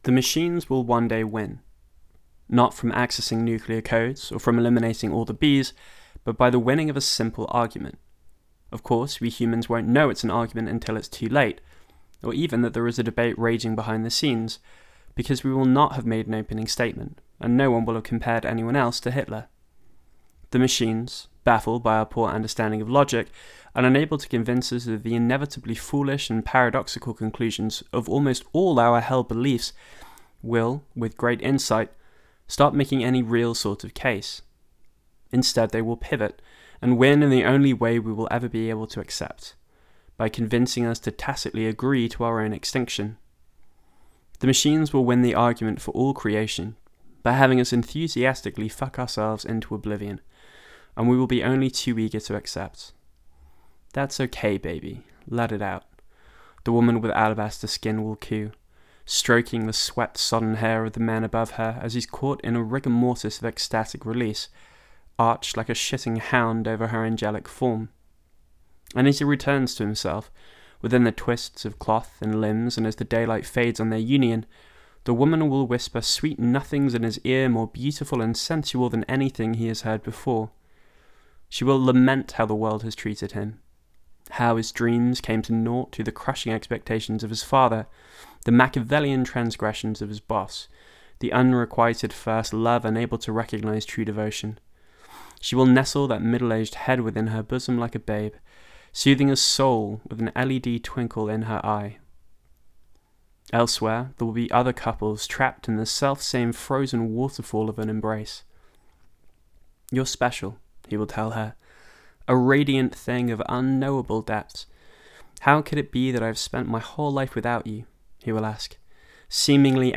Techno Laments v.1.mp3